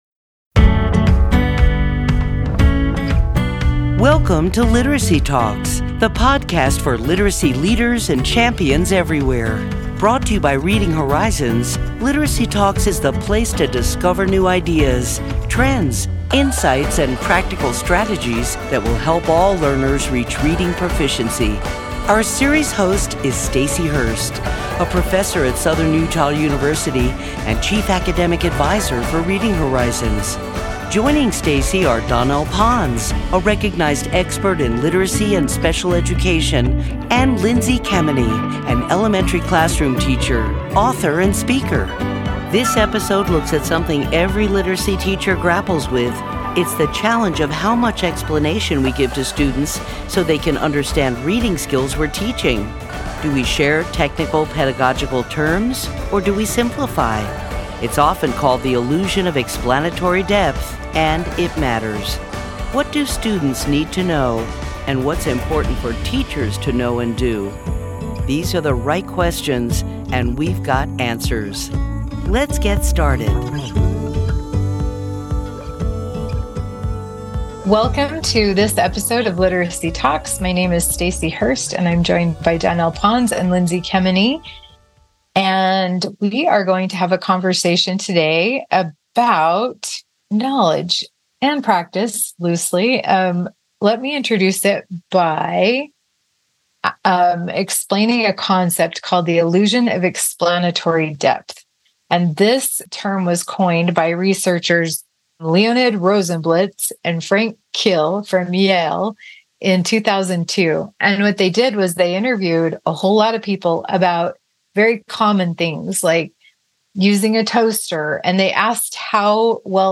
In this episode, our trio of literacy experts looks at reading terminology and who needs to know what so learners become readers.